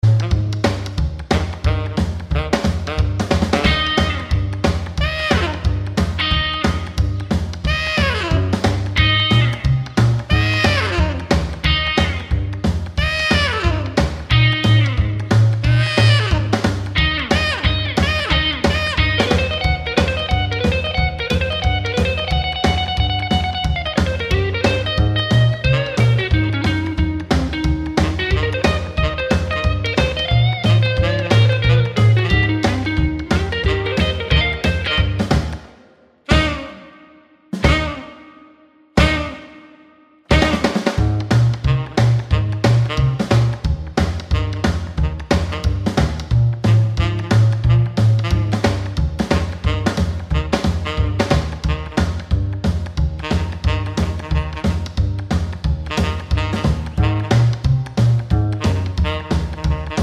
no Backing Vocals Rock 'n' Roll 2:20 Buy £1.50